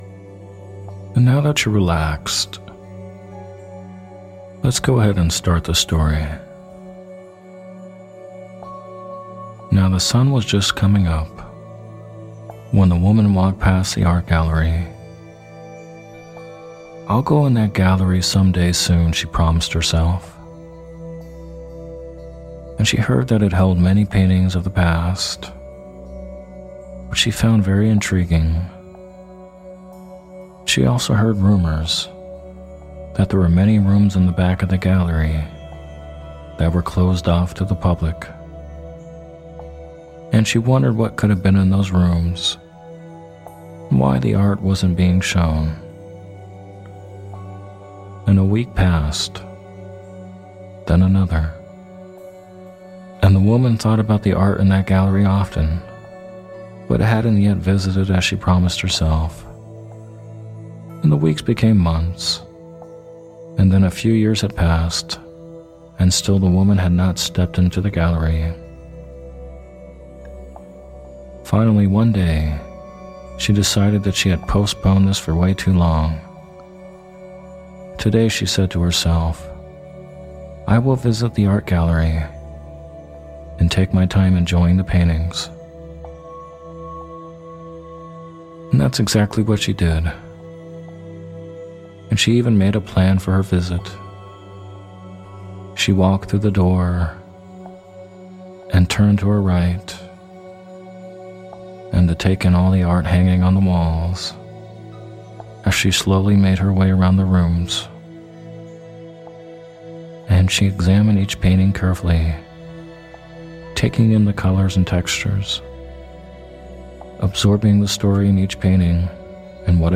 Story Based Meditation "The Art Gallery"